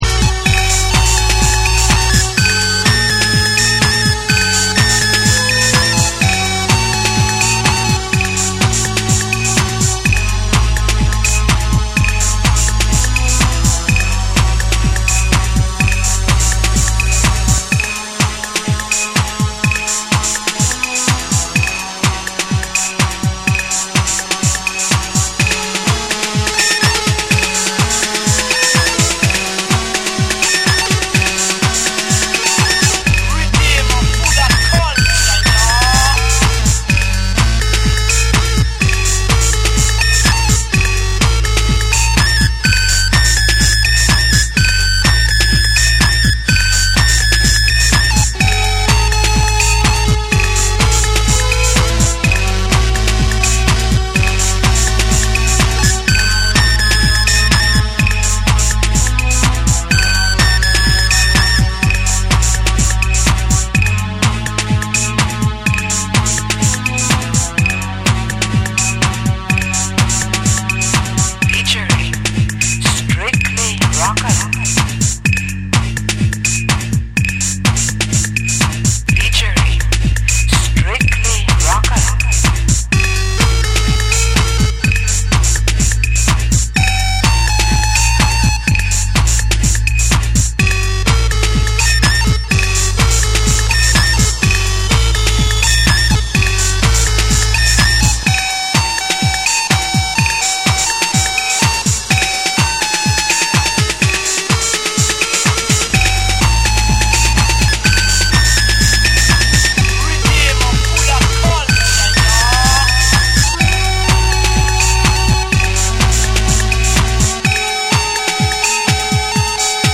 ハウス〜アシッド〜テクノ誕生期を象徴するクラシックを網羅した内容で
TECHNO & HOUSE